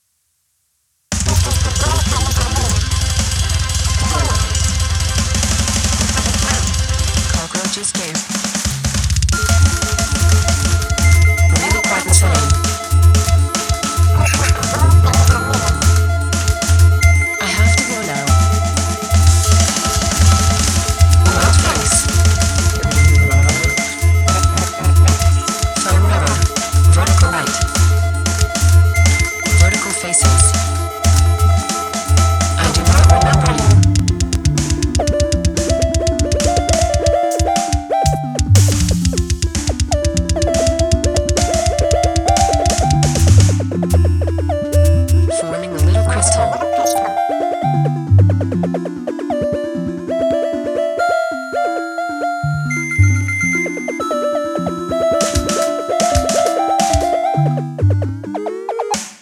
grindcore, hardcore, experimental, midicore, chiptune, ,